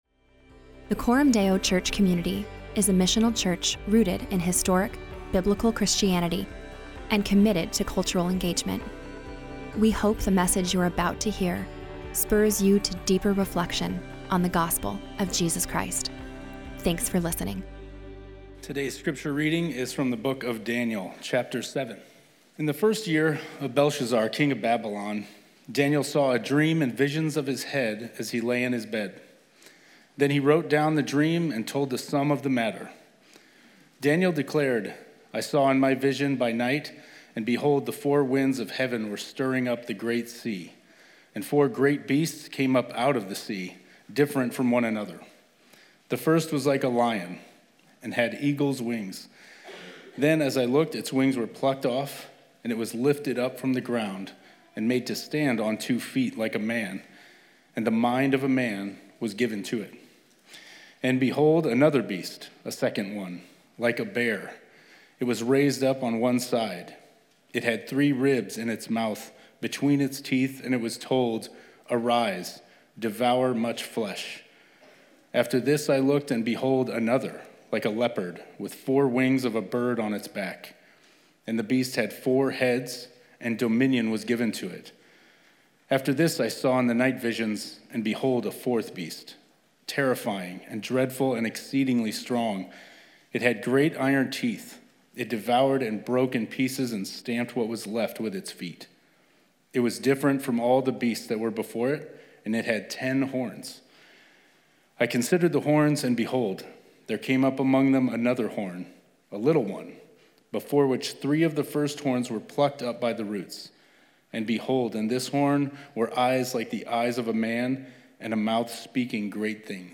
In this sermon, we explore the significance of this chapter and what it means for every human …